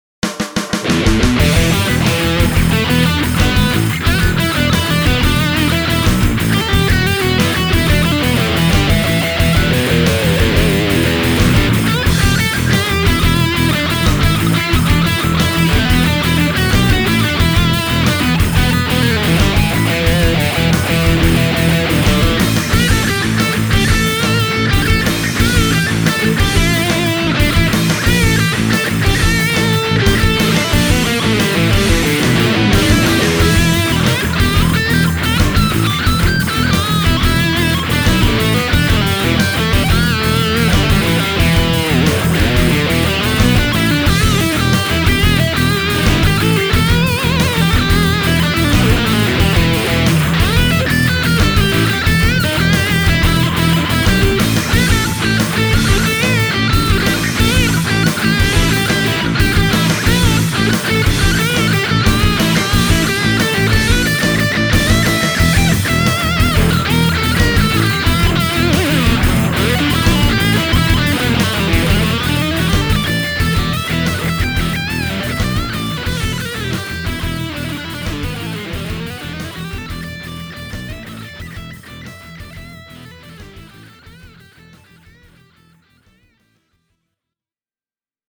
The demo song has been recorded with a Blackstar HT-1R combo. The rhythm guitars are the MH-1000NT (stereo left) and the M-400M (right). The MH-1000NT takes the first solo, with the M-400M going second.